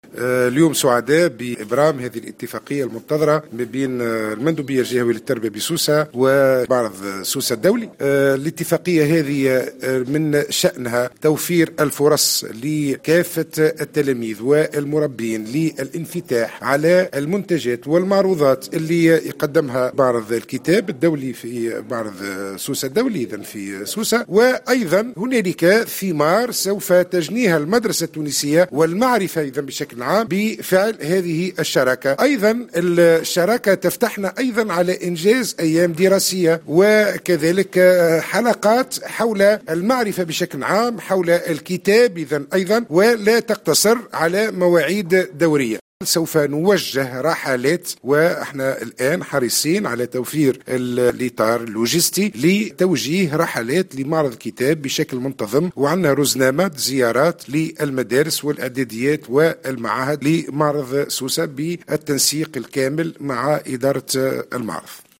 تم اليوم السبت 24 مارس 2018، ابرام اتفاقية بين معرض سوسة الدولي والمندوبية الجهوية للتربية بسوسة، تمثّل فرصة للتلاميذ والمدرسين للانفتاح على المنتجات التي يقدمها معرض الكتاب بسوسة، وفق ما أكده مندوب التربية نجيب الزبيدي في تصريح لمراسلة الجوهرة اف ام.